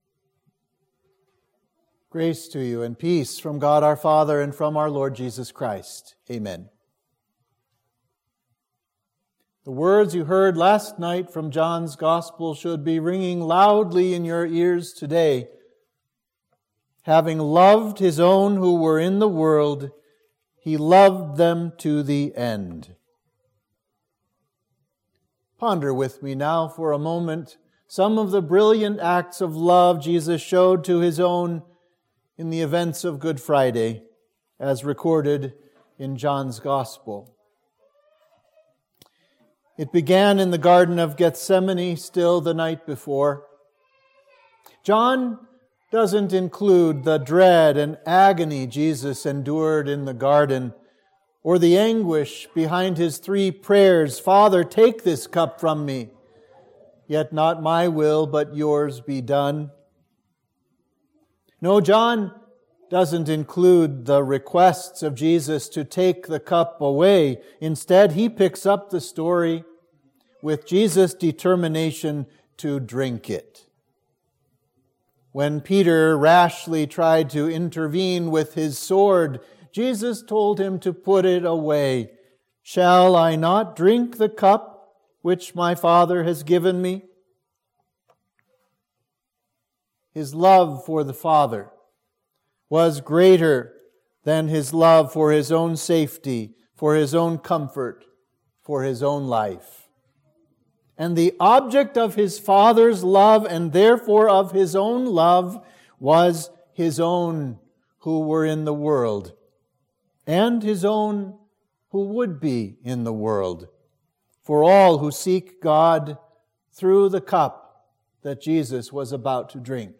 Sermon for Good Friday